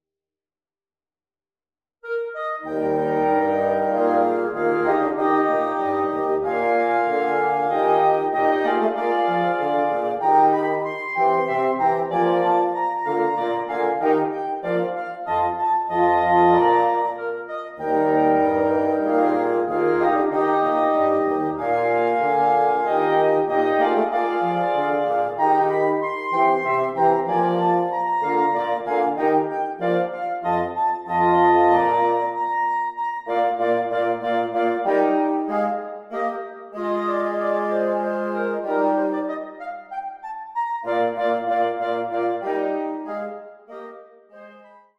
2 clarinets, 2 horns, 2 bassoons
(Audio generated by Sibelius/NotePerformer)